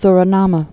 (srə-nämə) Formerly (1948-1978) Su·ri·nam (srə-năm, -näm)